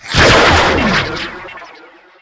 level_checkpoint.wav